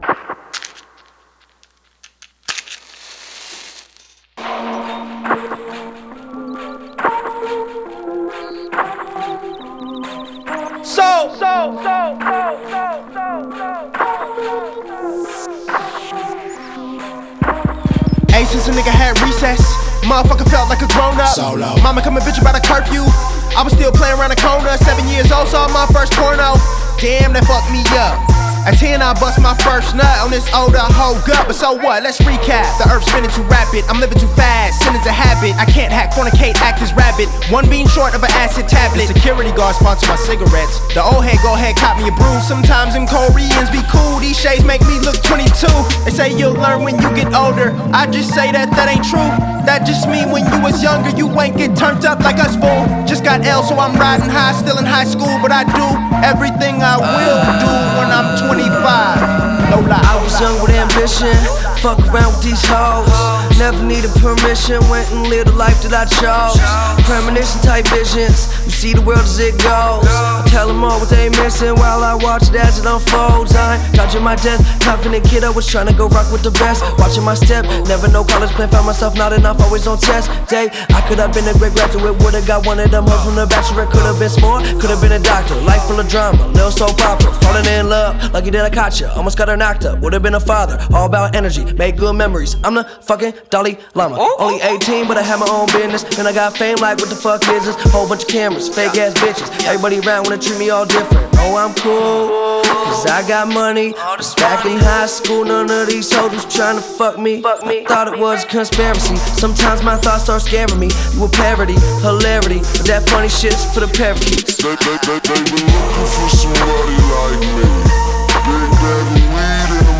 This record is soothing at night.
sounds like a nursery rhyme at times
It’s charming and particular.
The hip hop that’s out there today is hit or miss.
It’s orchestral and mechanic at the same time.